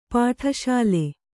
♪ pāṭha ṣāle